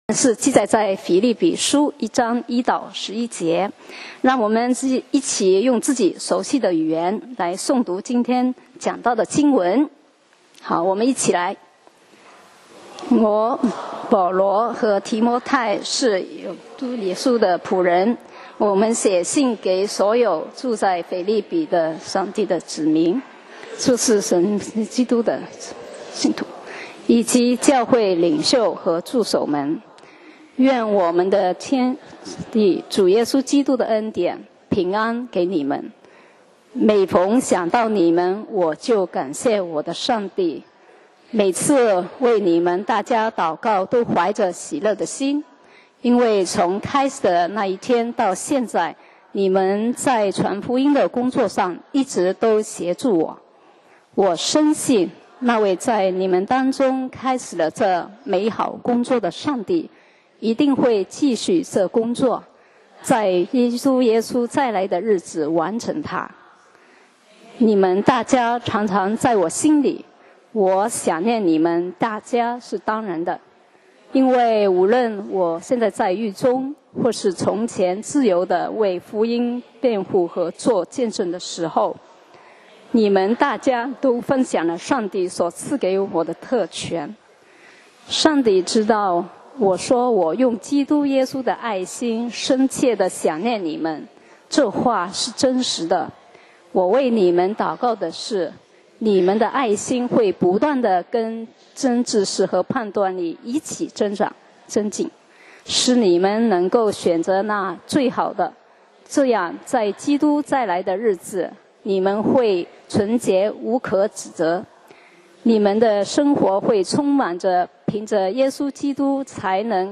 講道 Sermon 題目 Topic：一個值得感恩的教會 經文 Verses：腓立比書 1:1-11 1基督耶稣的仆人保罗，和提摩太，写信给凡住腓立比，在基督耶稣里的众圣徒，和诸位监督，诸位执事。